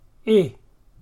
ɪ
ɪ-individual.mp3